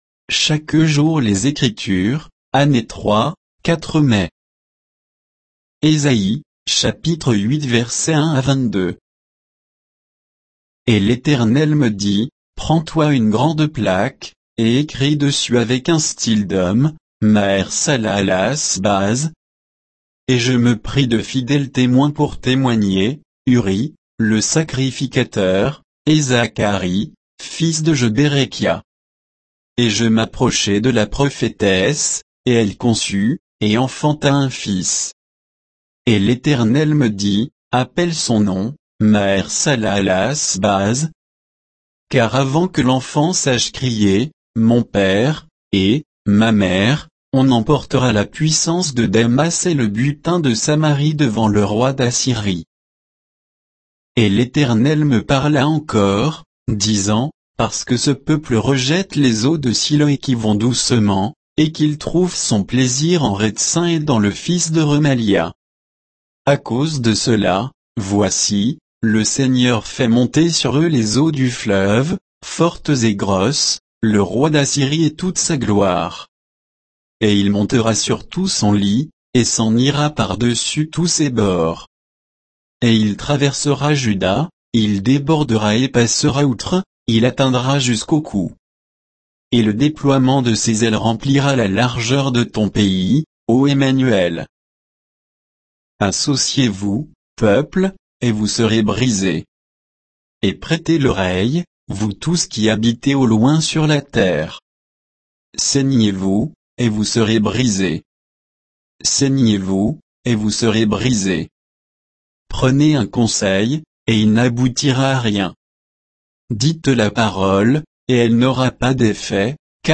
Méditation quoditienne de Chaque jour les Écritures sur Ésaïe 8